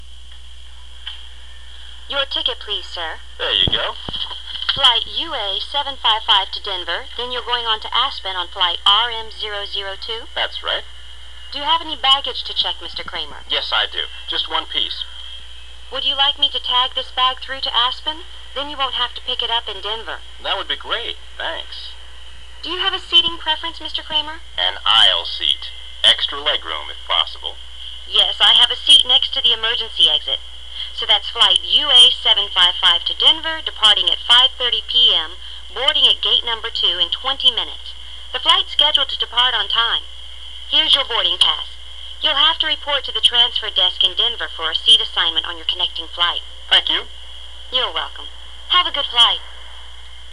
英語發音 English Pronunciation